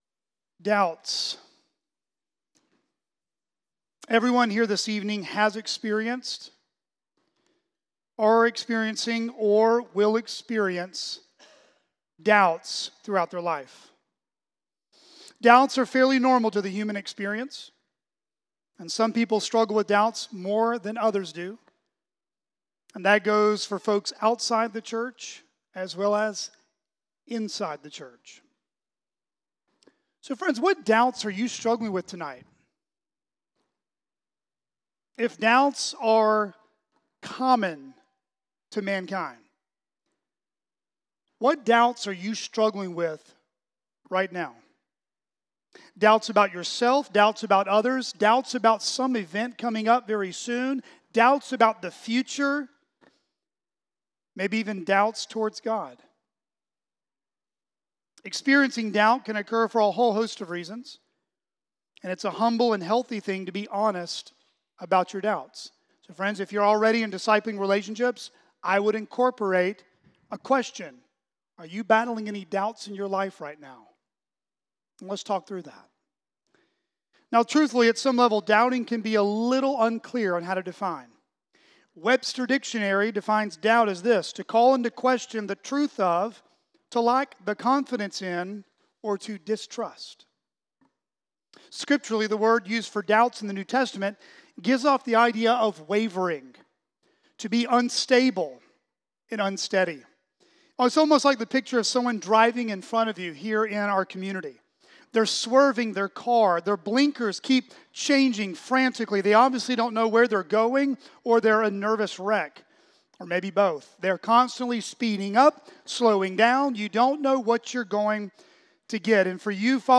CCBC Sermons